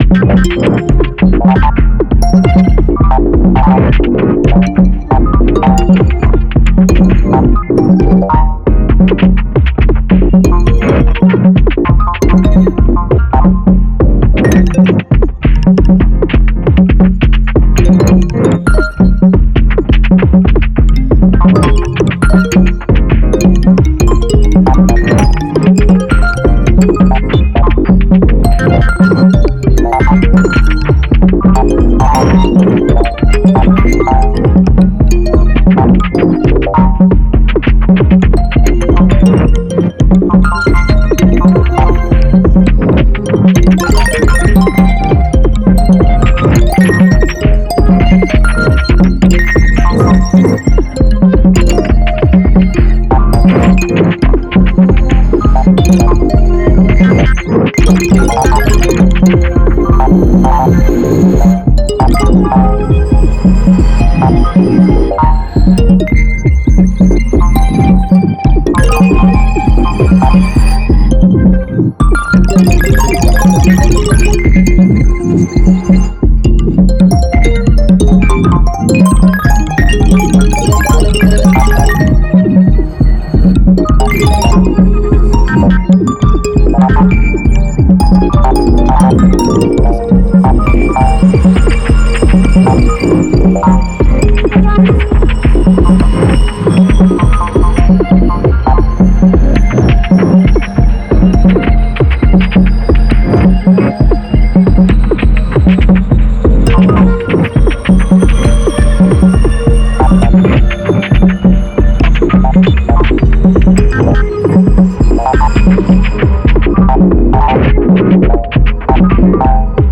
bleep bloop